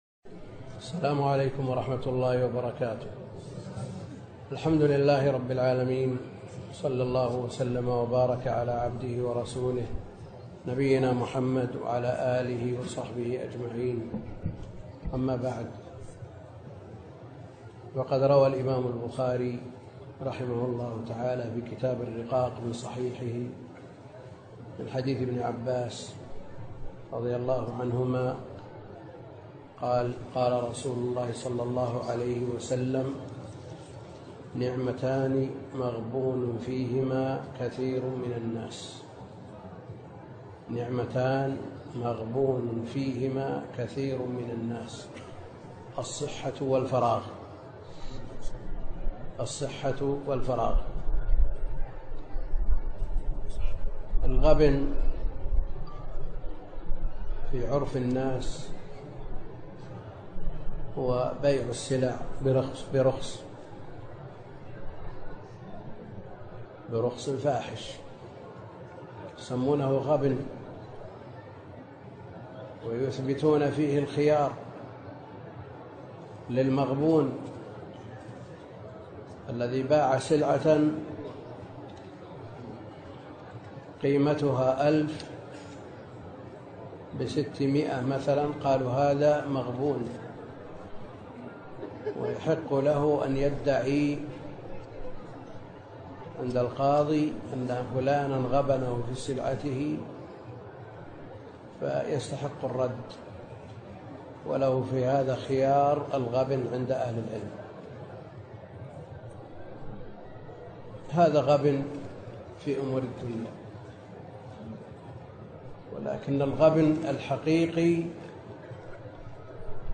محاضرة - استغلال الوقت